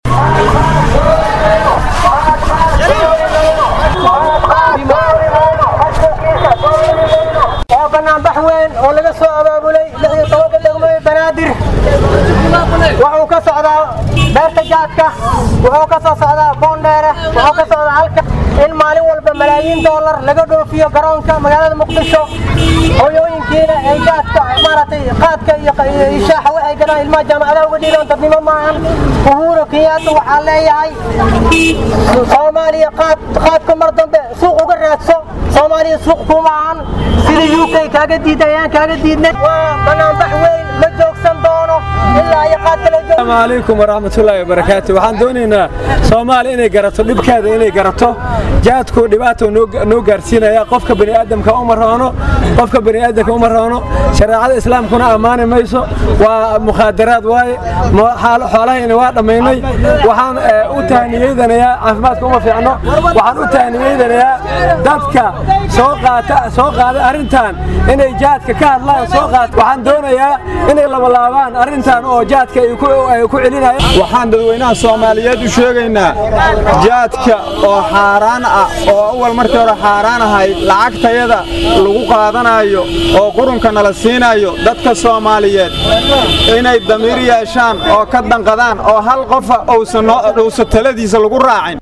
Banaanbaxa ayaa xoogiisa wuxuu ka dhacay Beerta Qaadka, Suuqa Bakaaraha Degmada Boondheere iyo xaafado kale, iyadoo dadka Banaanbaxa dhigayay ay ku qeylinayeen erayo ay kaga soo horjeedaan Qaadka ay Dowladda Soomaaliya dib u fasaxday.
Dadkii halkaasi ka hadley hadaladooda waxaa ka mid ahaa.